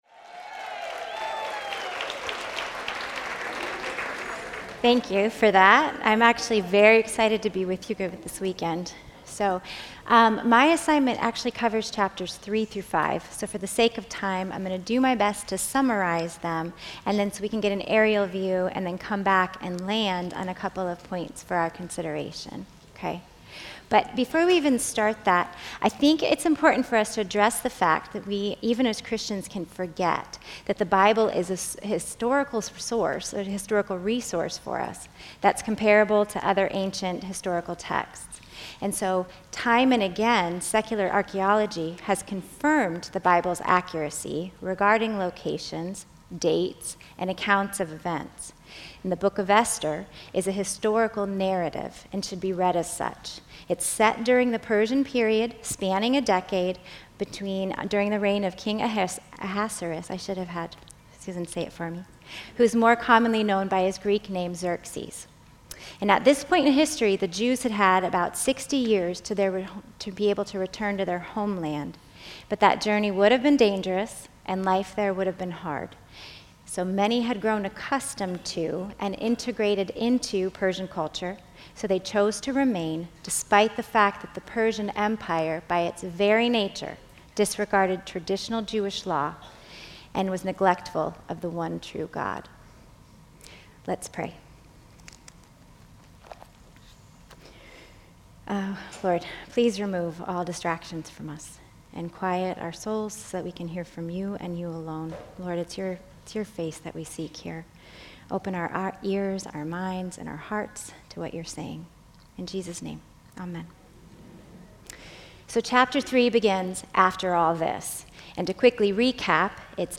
Women's Retreat 2025